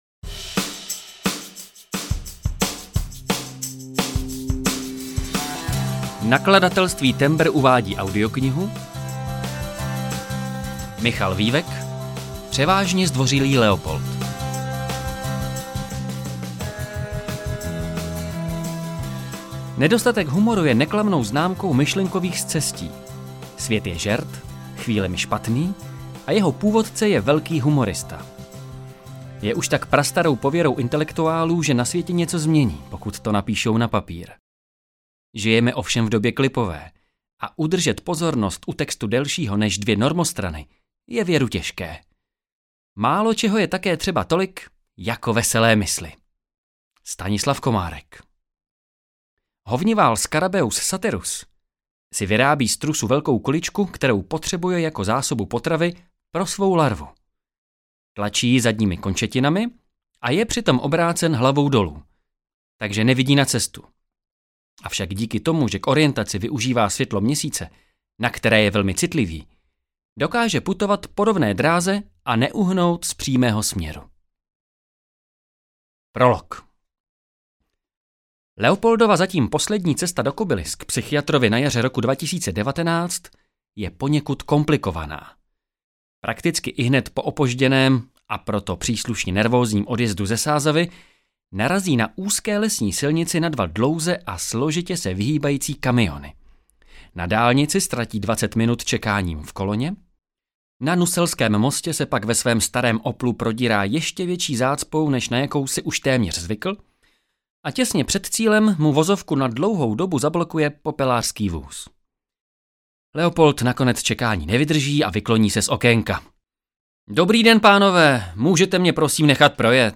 Převážně zdvořilý Leopold audiokniha
Ukázka z knihy